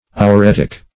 ouretic - definition of ouretic - synonyms, pronunciation, spelling from Free Dictionary Search Result for " ouretic" : The Collaborative International Dictionary of English v.0.48: Ouretic \Ou*ret"ic\, a. [Gr.